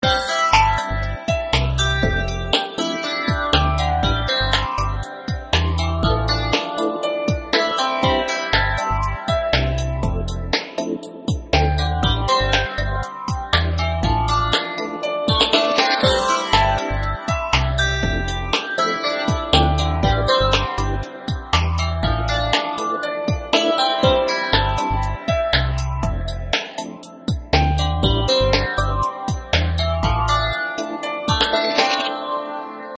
Очень летний трек))))